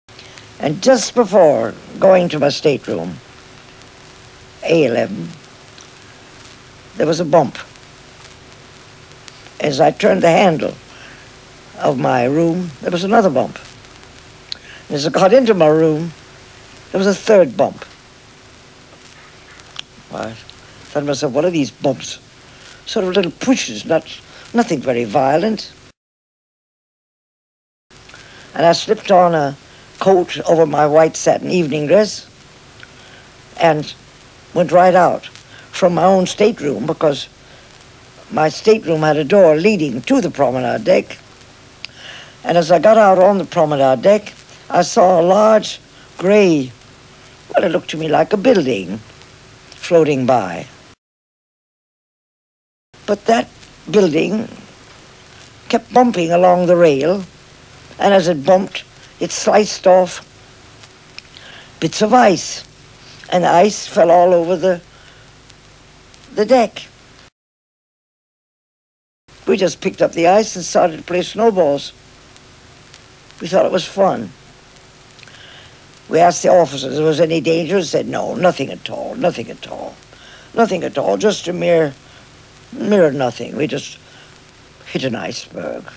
Qui potete ascoltare le voci di chi visse, in prima persona, il disastro del TITANIC nel 1912. Queste registrazioni originali sono state gentilmente concesse dall'Archivio della BBC.
passeggera di prima classe.